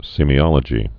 (sēmē-ŏlə-jē, sĕmē-, sēmī-)